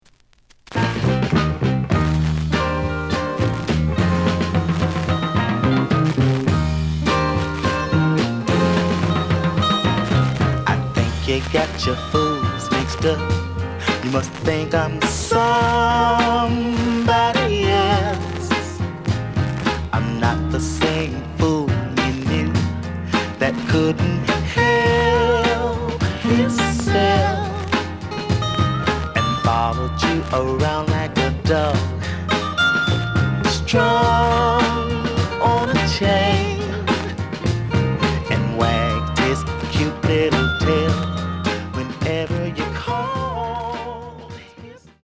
The audio sample is recorded from the actual item.
●Genre: Soul, 60's Soul
Noticeable noise on B side.)